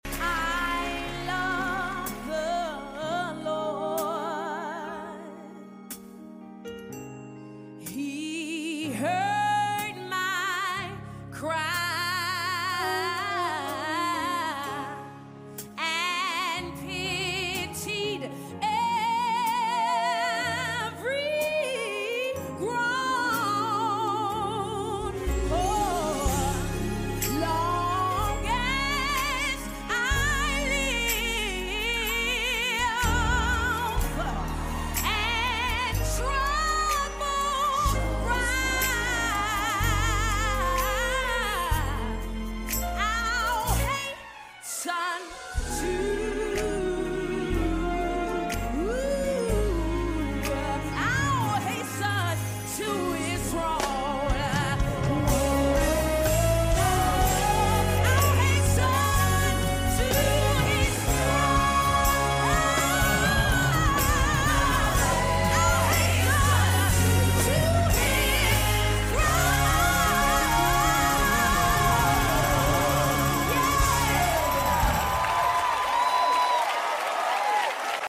A passionate contemporary gospel performer, sound effects free download
praise & worship music